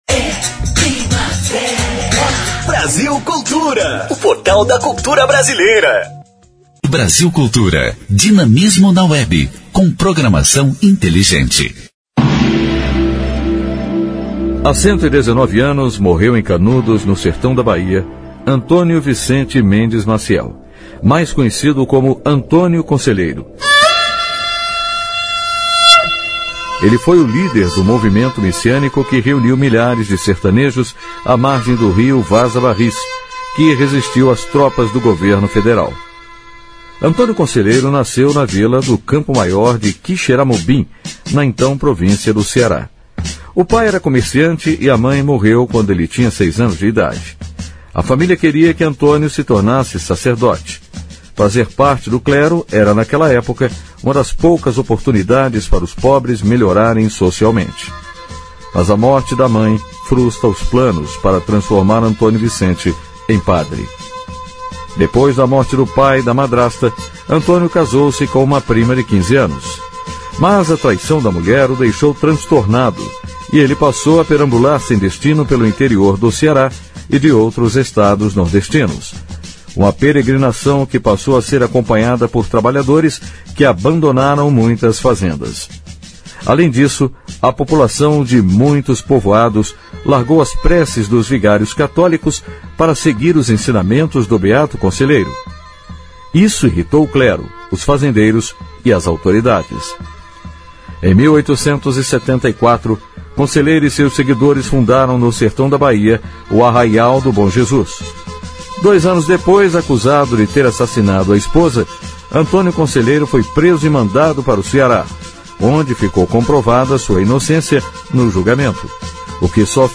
História Hoje: Programete sobre fatos históricos relacionados às datas do calendário. Vai ao ar pela Rádio Brasil Cultura de segunda a sexta-feira.